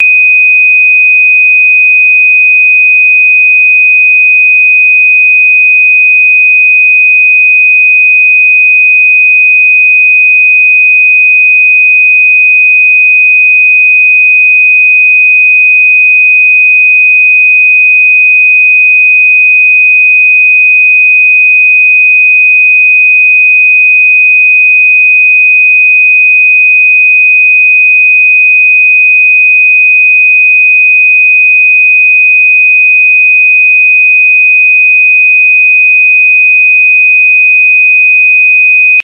60歳以上も聞こえる音。